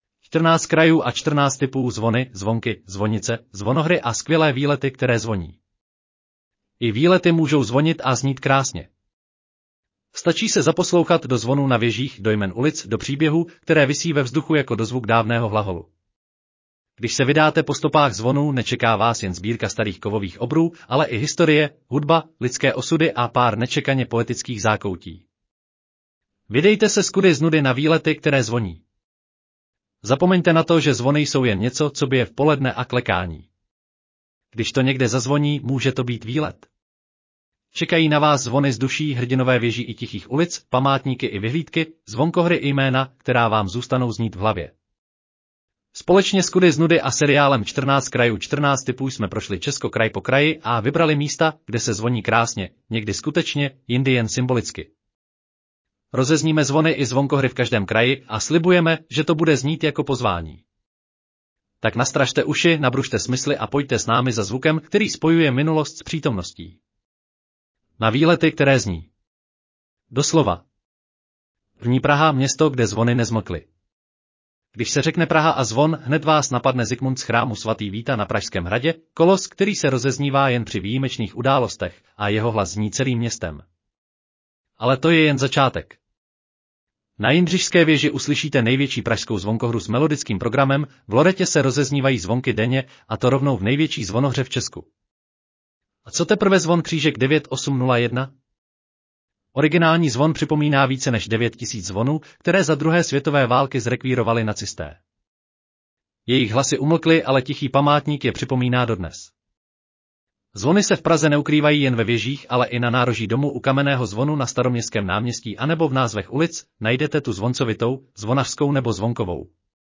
Audio verze článku Čtrnáct krajů & čtrnáct tipů: zvony, zvonky, zvonice, zvonohry a skvělé výlety, které zvoní